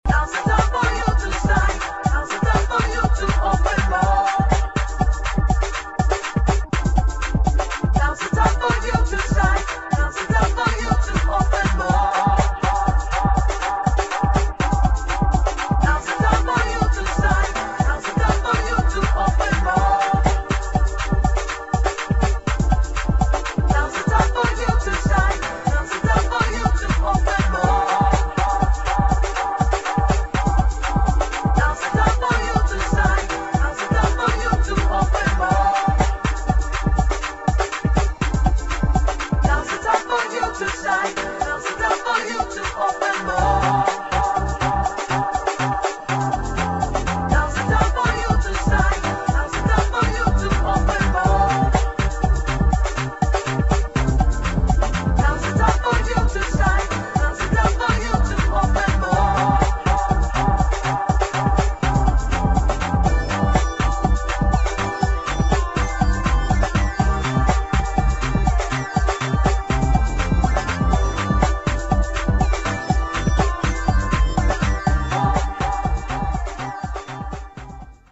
[ HOUSE / JAZZ HOUSE ]